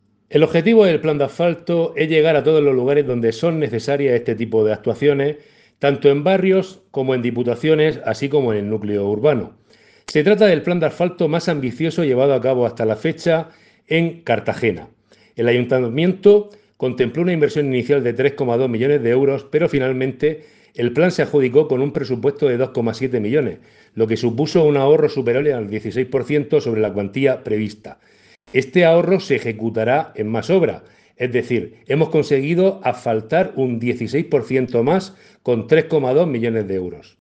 Enlace a Declaraciones de Diego Ortega